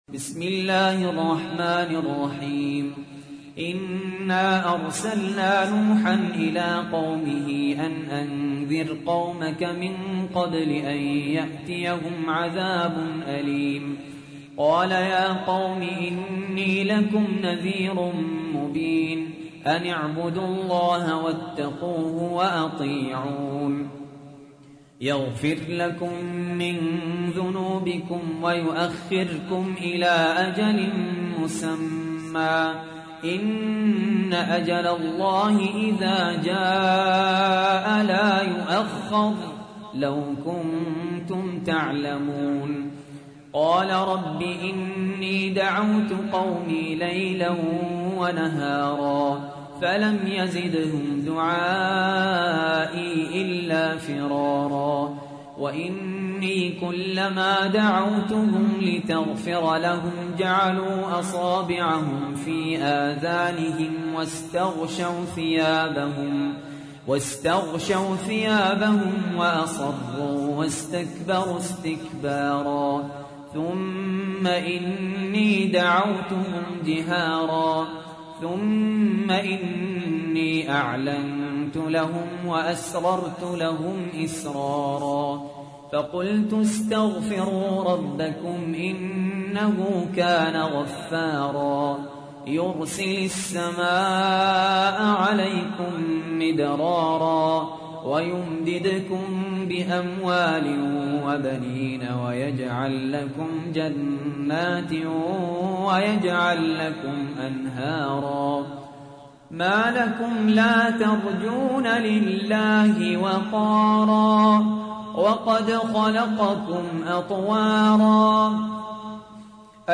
تحميل : 71. سورة نوح / القارئ سهل ياسين / القرآن الكريم / موقع يا حسين